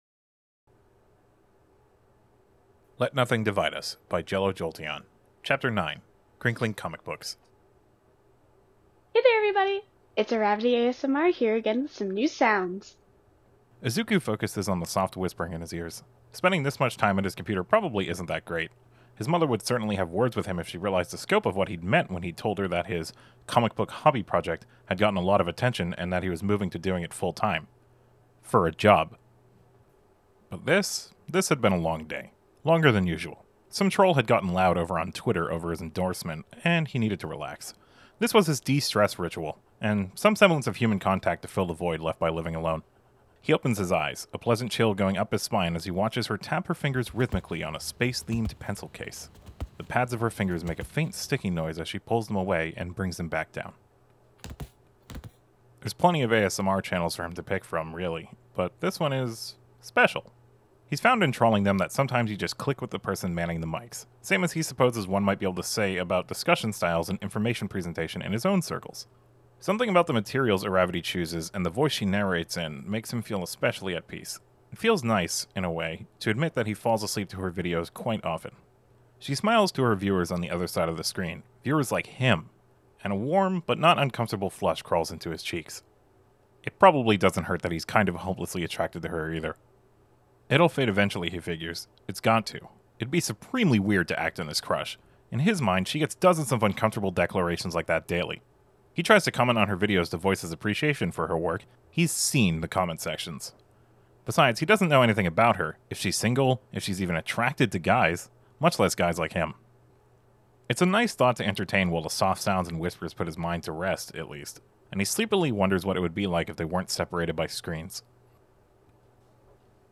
Voice of Ochako Uraraka
" Stirring in a cup of tea 1 " by Anti-HeroAnnie This sound is licensed under CC BY-NC 4.0 .